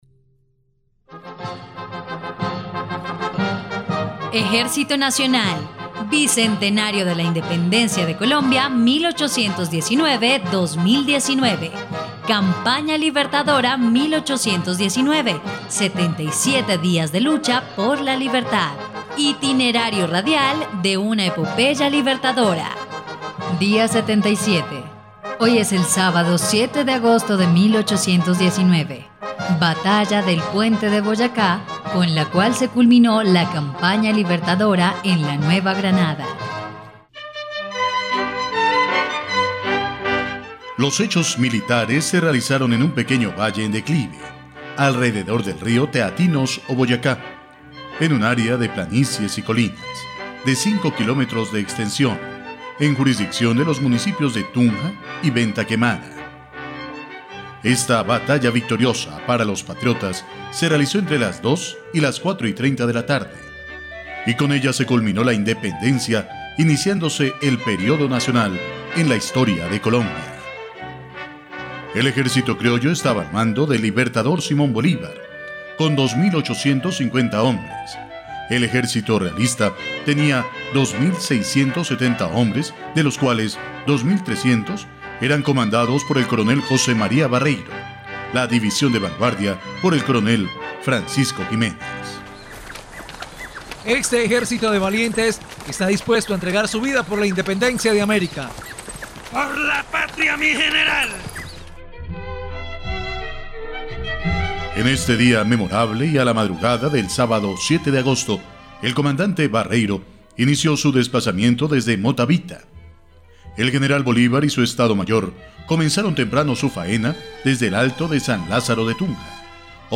dia_77_radionovela_campana_libertadora.mp3